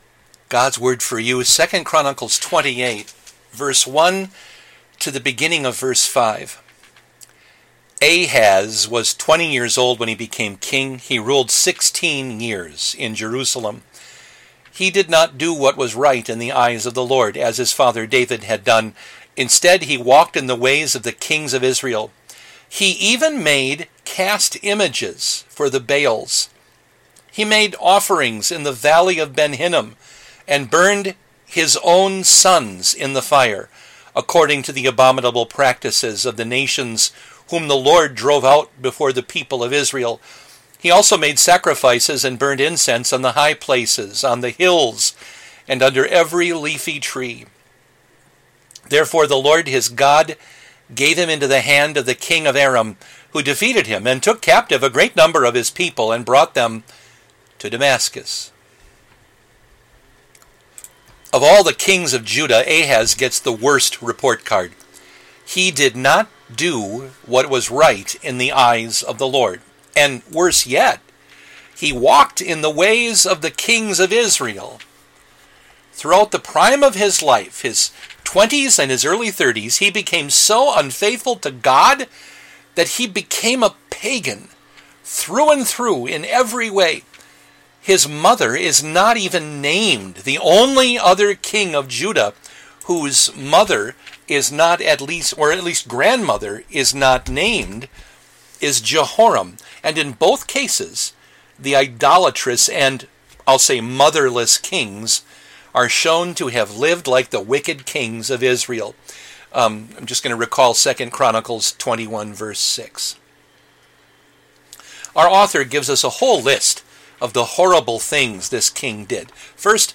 A daily devotion